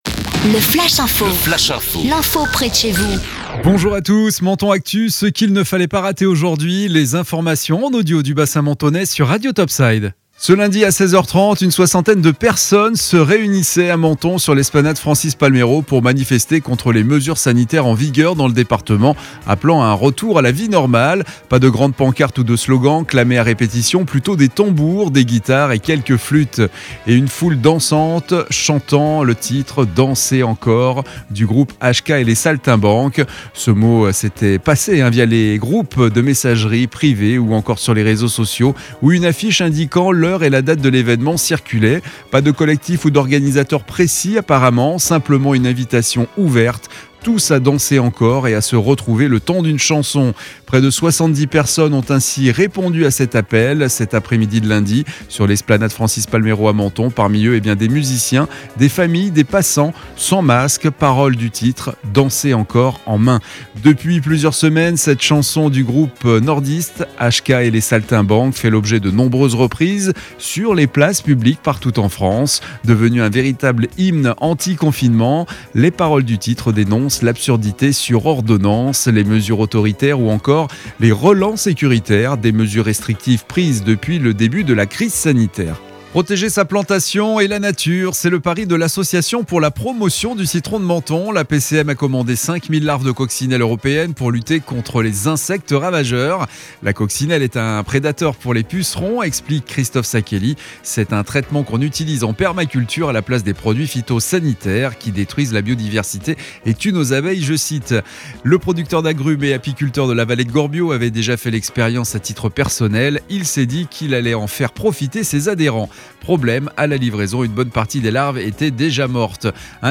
Menton Actu - Le flash info du mardi 6 avril 2021